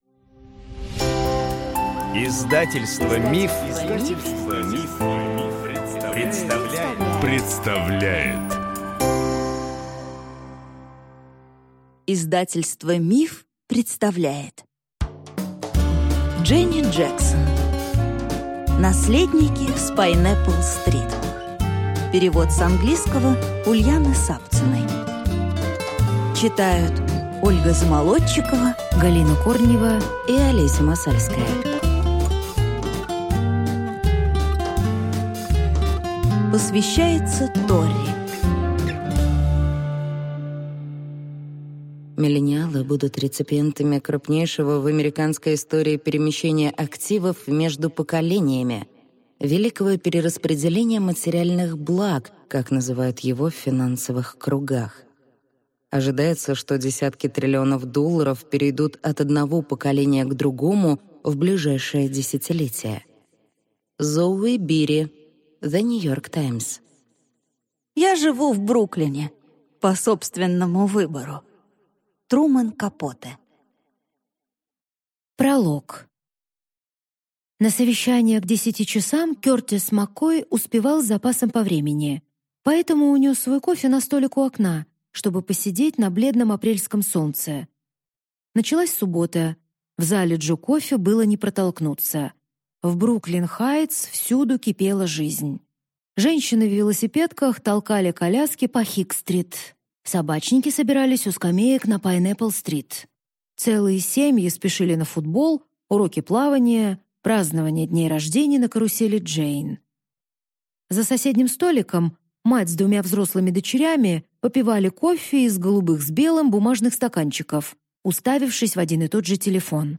Аудиокнига Наследники с Пайнэппл-стрит | Библиотека аудиокниг